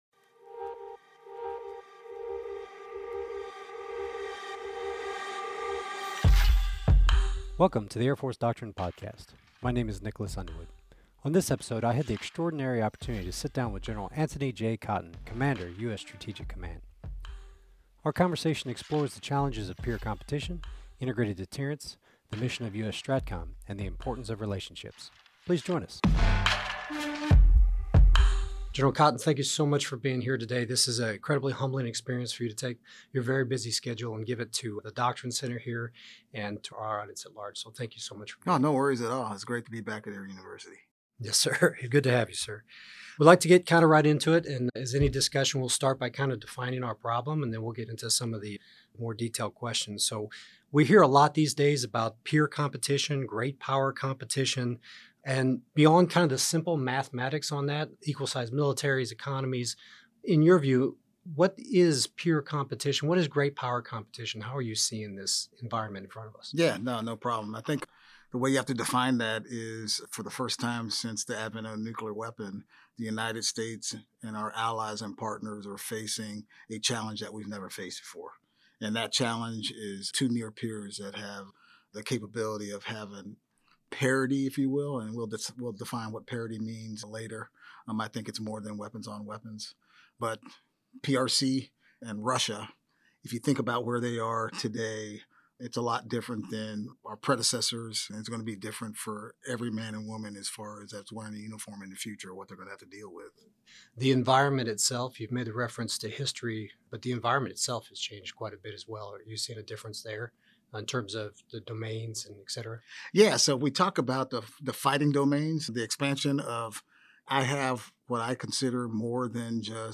On this episode we sit down with General Anthony J Cotton, Commander US Strategic Command.